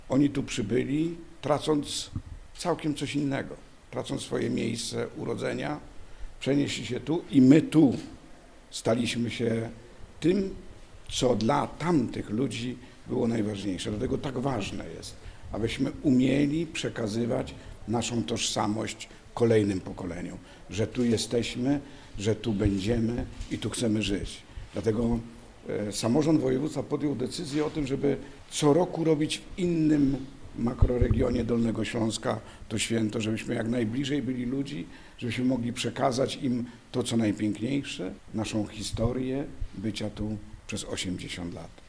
Jerzy Pokój – przewodniczący Sejmiku przypomina o trudnych losach pierwszych powojennych mieszkańców regionu.